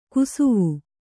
♪ kusuvu